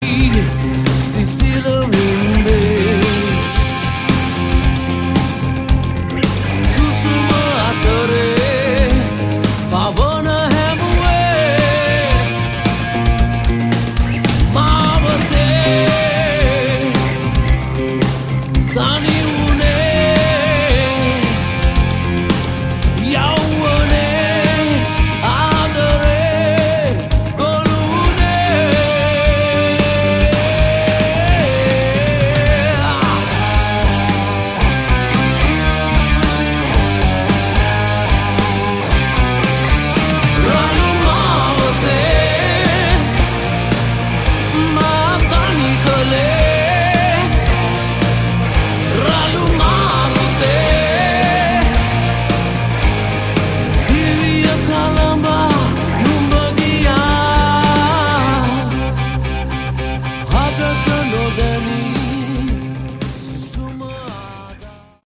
Category: Melodic Rock
vocals
guitar
bass
drums